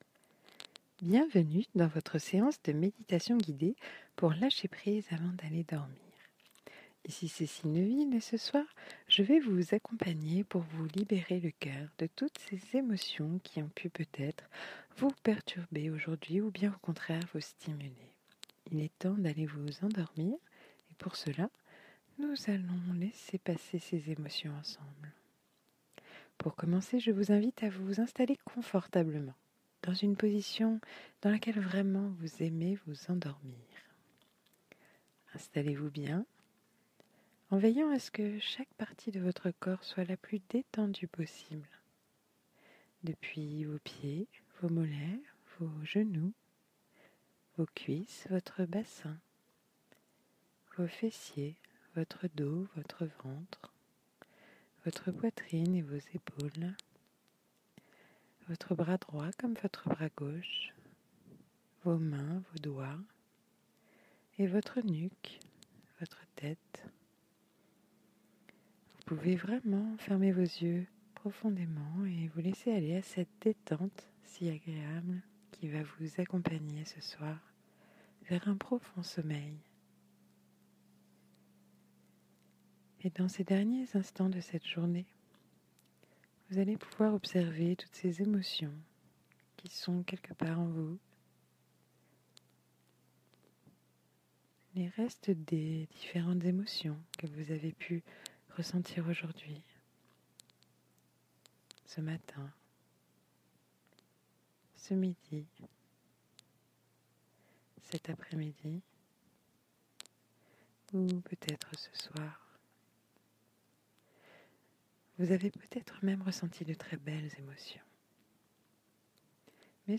7 méditations positives audio